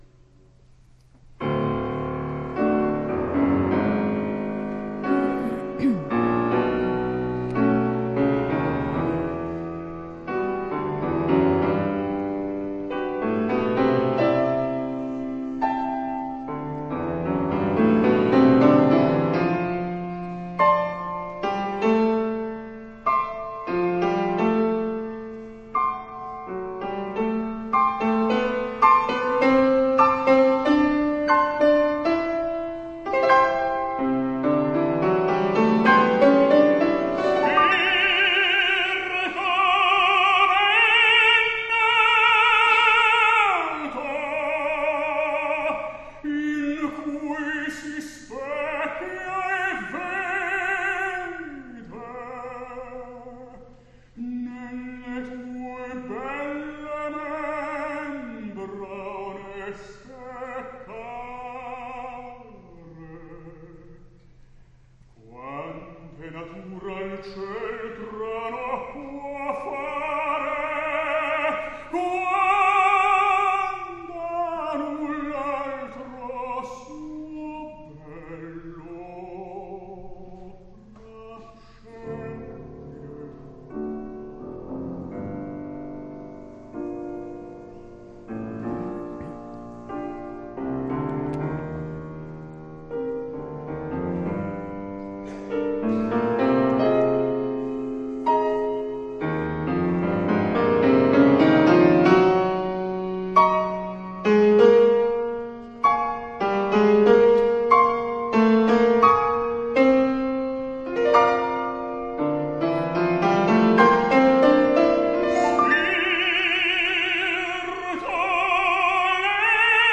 Liederabend, München, Prinzregententheater, 22. Juli 2008
(anhören) verabschiedete sich Kaufmann heldisch strahlend in die Pause.